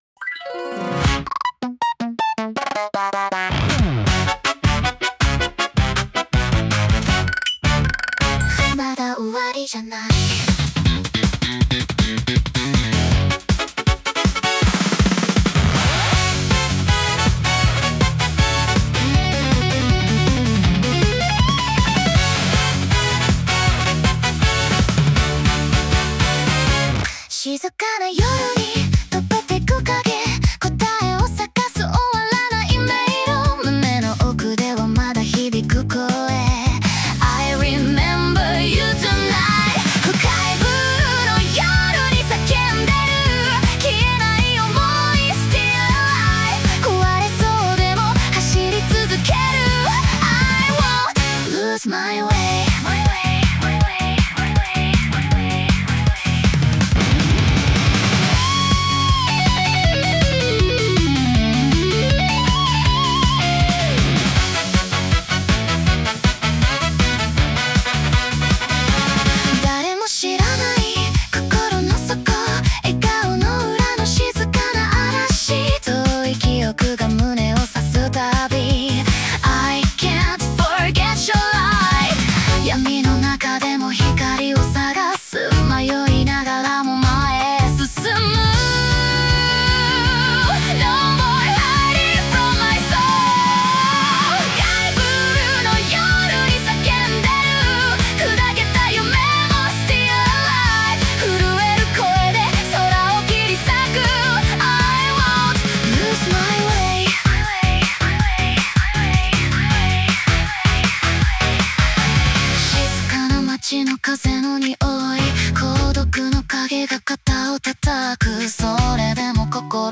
女性ボーカル
イメージ：アバンギャルド・ポップ,エレクトロニック・ポップ,グリッチ・ポップ,女性ボーカル